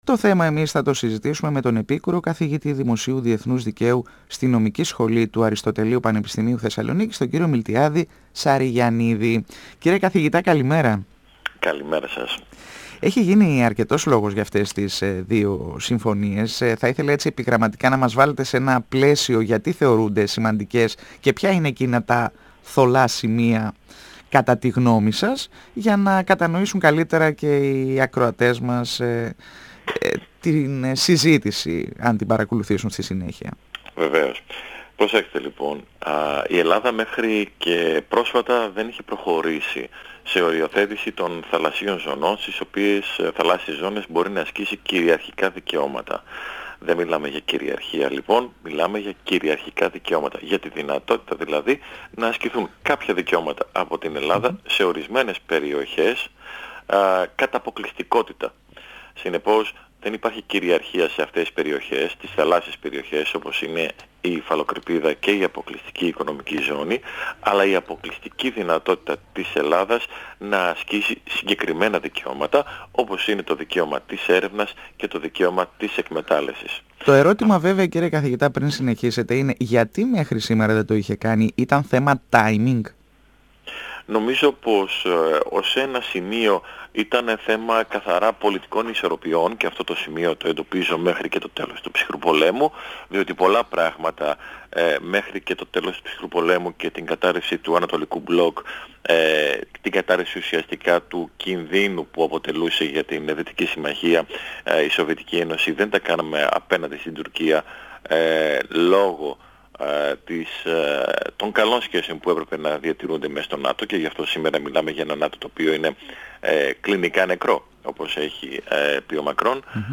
μιλώντας στον 102 fm ΕΡΤ3.